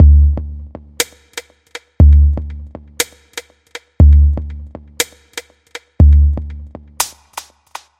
环境回声大鼓
标签： 120 bpm Ambient Loops Drum Loops 689.11 KB wav Key : Unknown
声道单声道